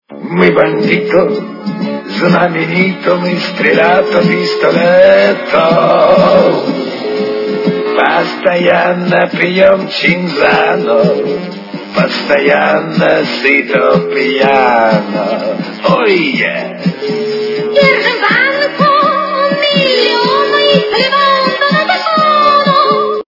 - из мультфильмов
При заказе вы получаете реалтон без искажений.